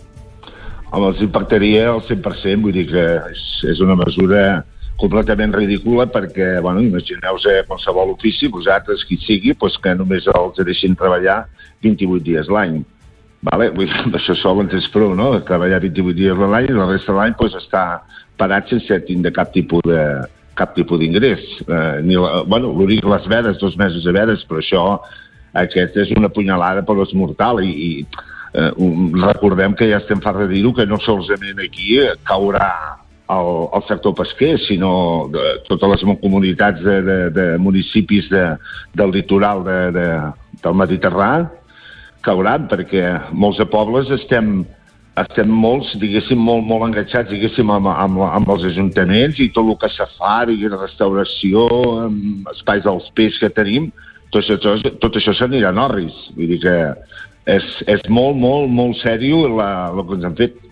En una entrevista durant el Supermatí